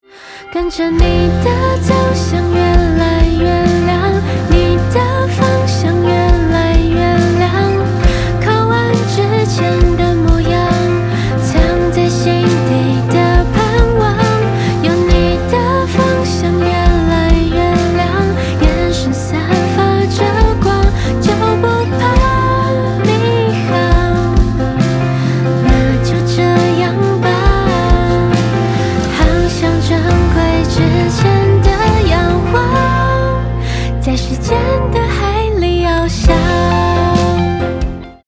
Pop folk, Chinese pop